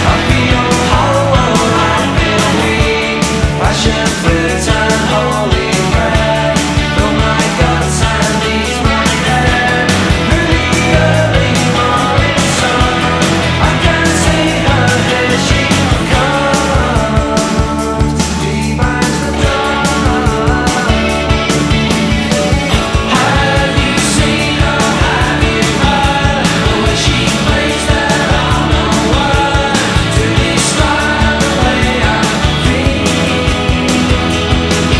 Жанр: Поп музыка / Рок / Альтернатива / Электроника